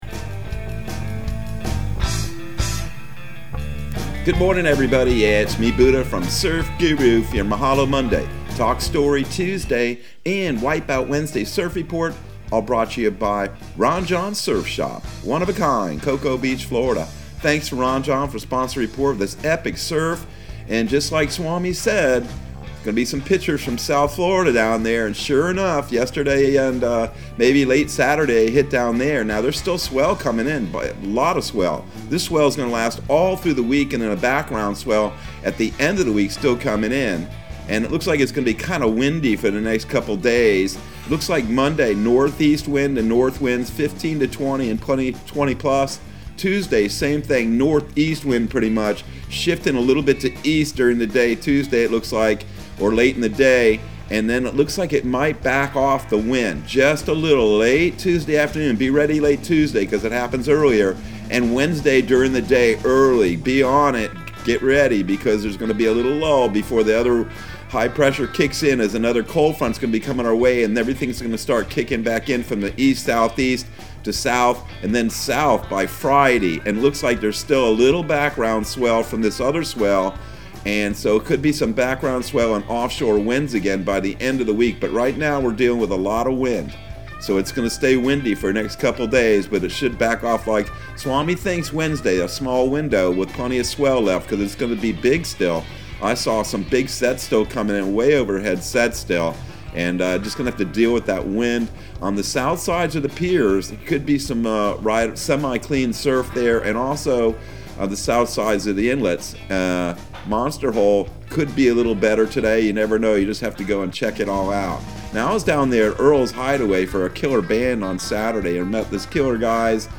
Surf Guru Surf Report and Forecast 11/08/2021 Audio surf report and surf forecast on November 08 for Central Florida and the Southeast.